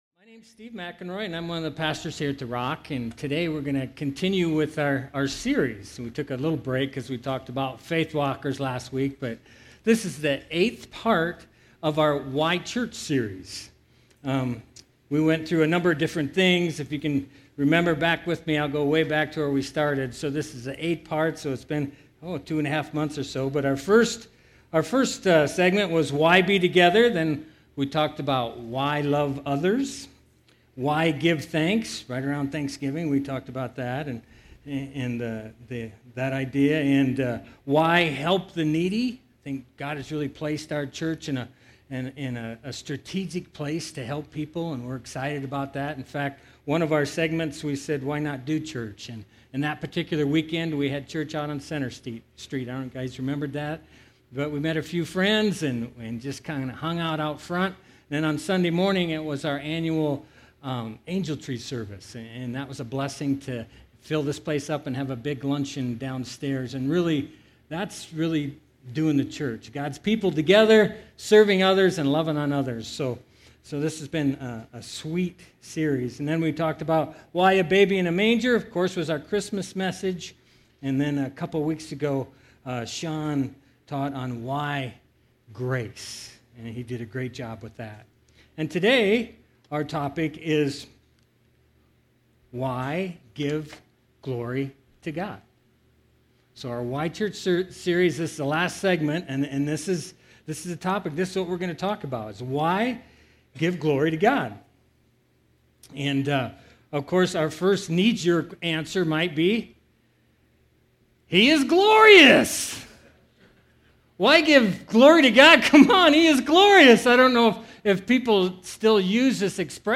Get Wisdom: Practical Wisdom for Life from the Book of Proverbs, is a 14-week sermon series from The Rock Church in Draper Utah.